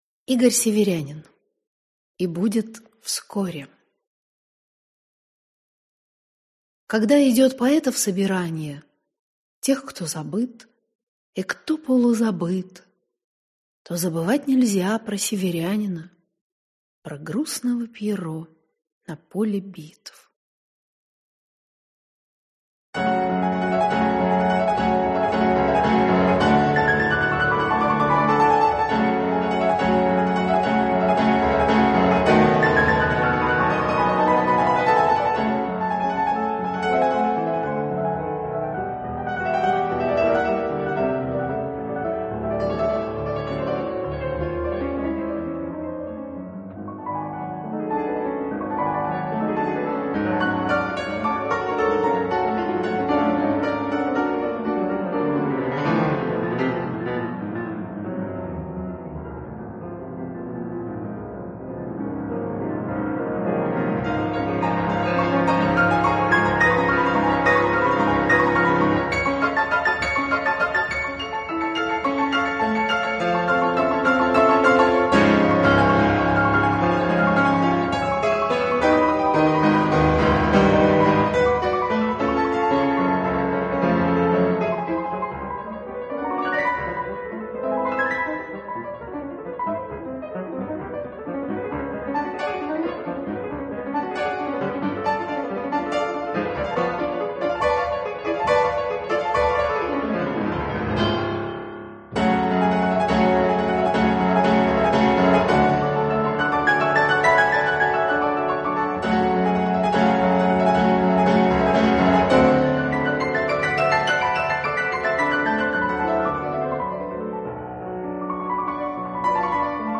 Аудиокнига И.Северянин «И будет вскоре…»